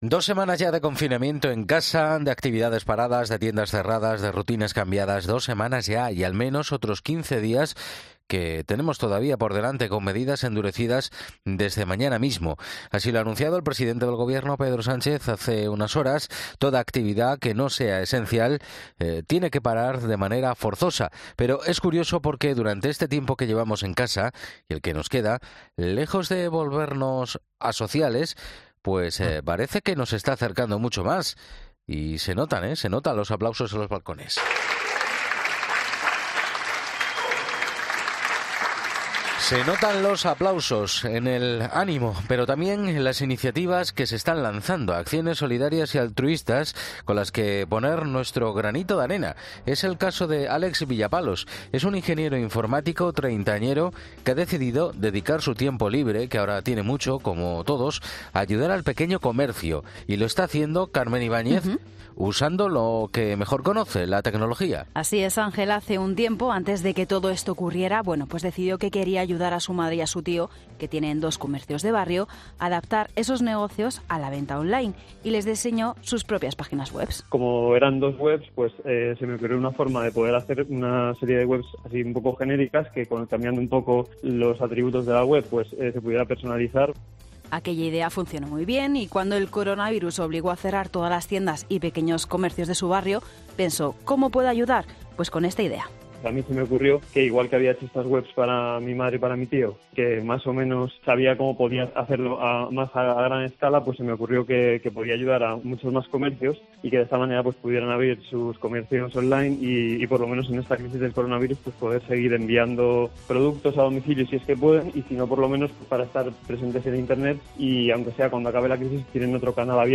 Si quieres conocer sus historias, no dudes en escuchar este fragmento que hemos emitido en La Mañana del Fin de Semana de COPE.